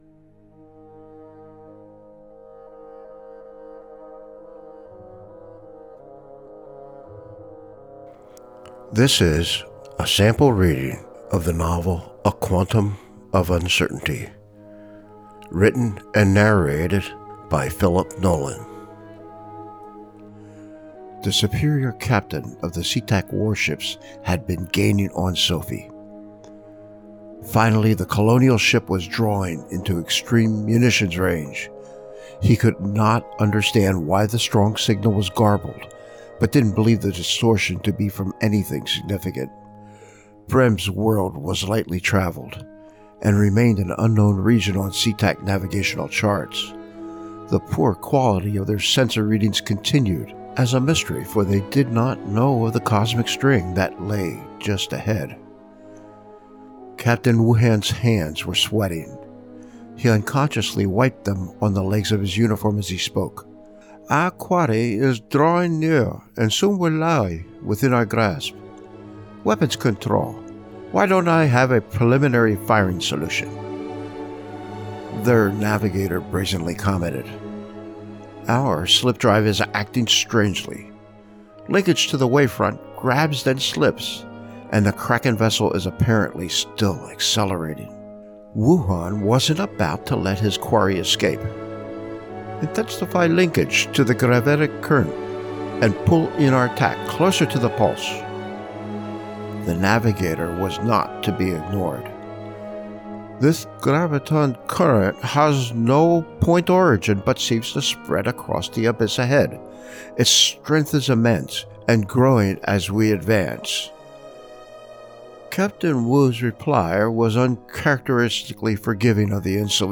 Audible Sample Reading